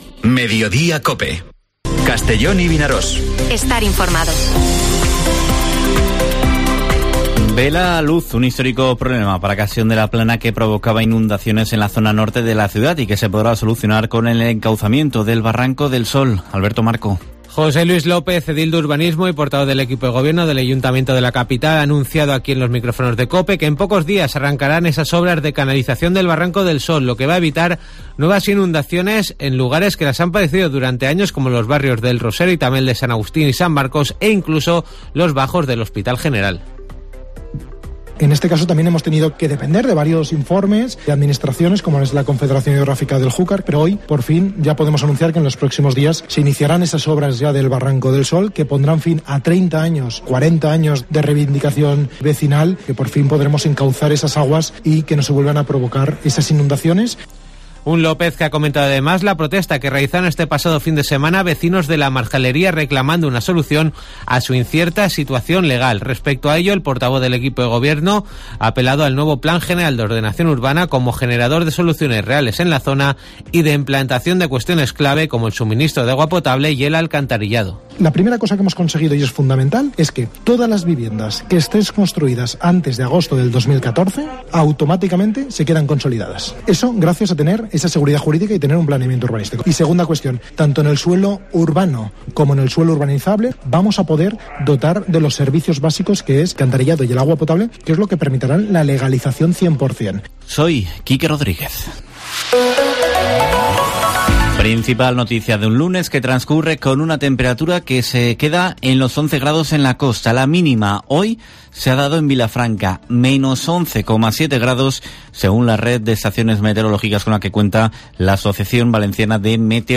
Informativo Mediodía COPE en la provincia de Castellón (30/01/2023)